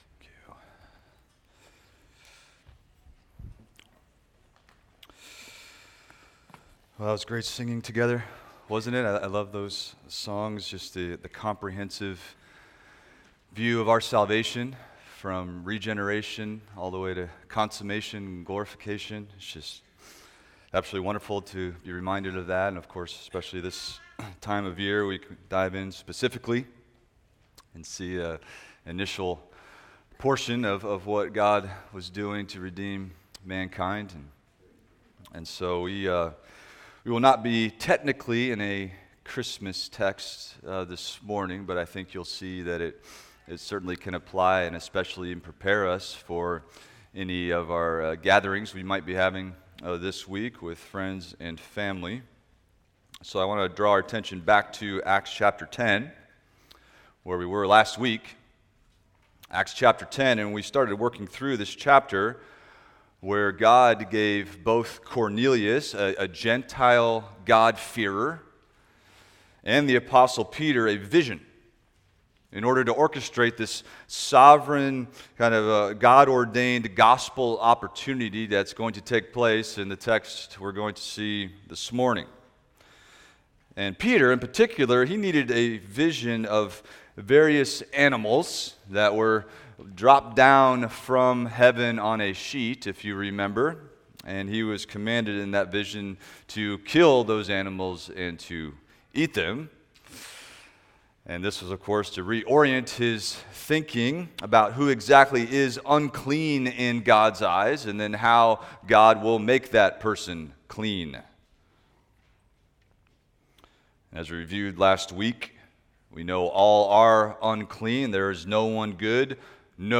Expository Preaching through the Book of Acts